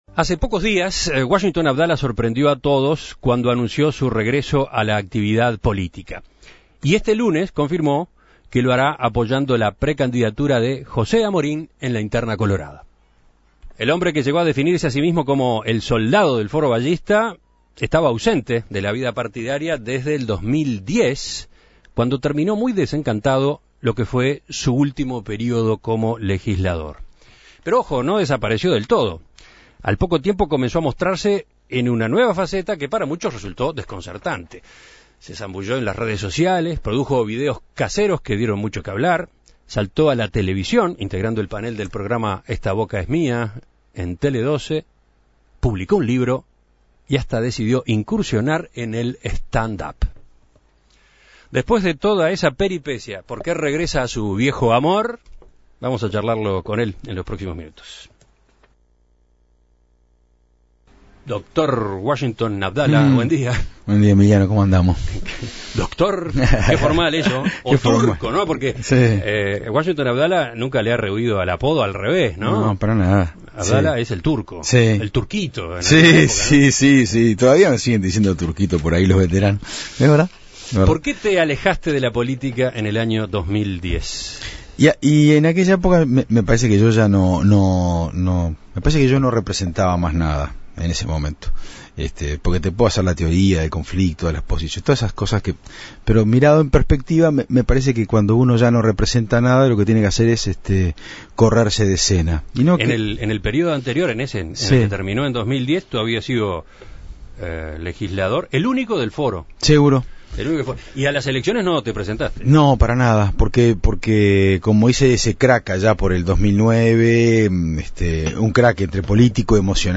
Con motivo de su vuelta a la política En Perspectiva conversó con él.